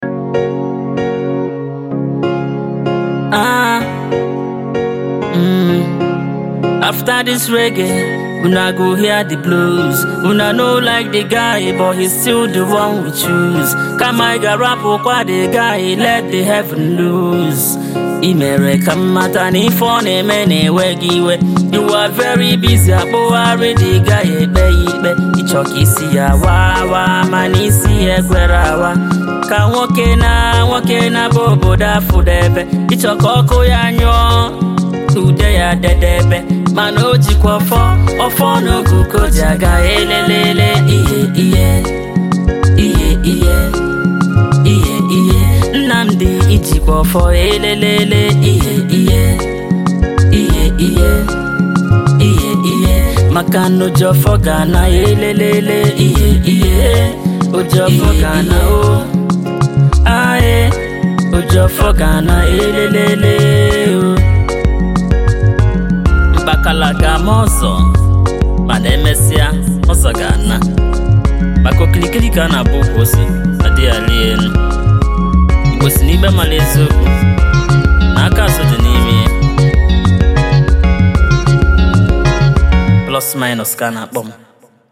His melody and harmony is straight to the soul.